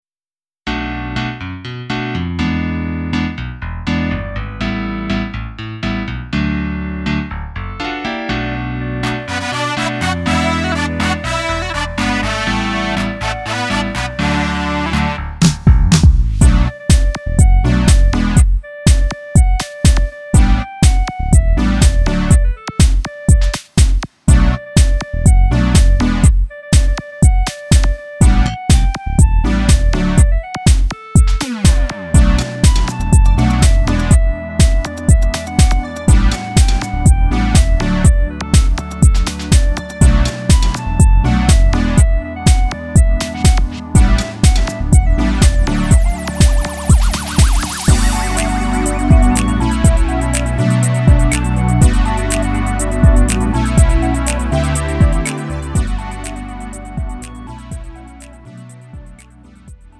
음정 원키 3:45
장르 가요 구분 Lite MR